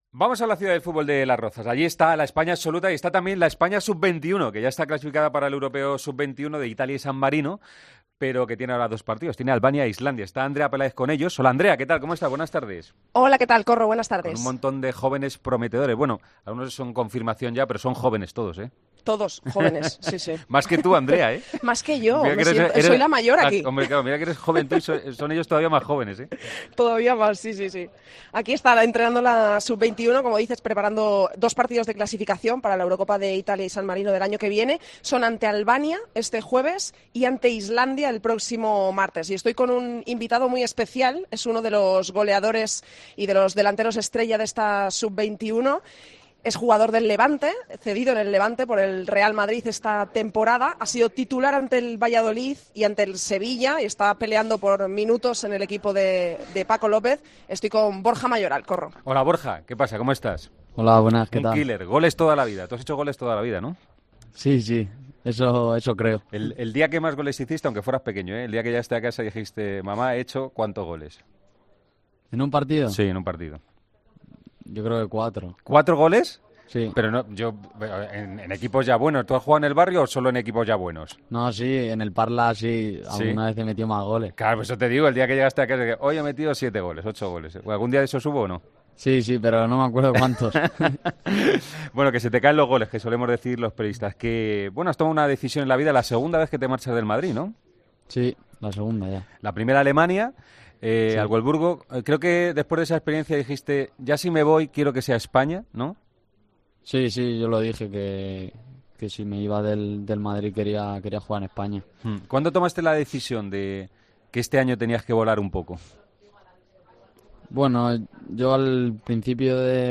El delantero de la selección española sub21 y actual ariete del Levante analizó el momento que vive en una entrevista en Deportes COPE.